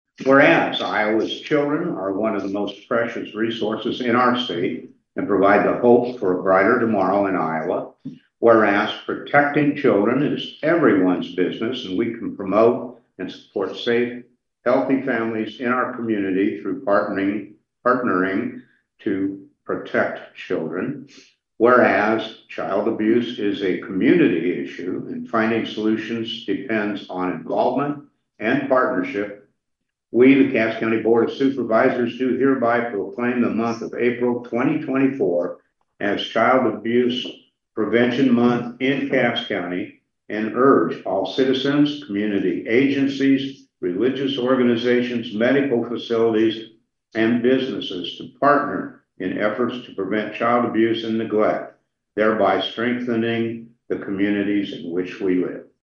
(Atlantic, Iowa) – The Cass County Board of Supervisors have proclaimed April as “Child Abuse Awareness Month.” Board Chair Steve Baier read the proclamation, a portion of which stated…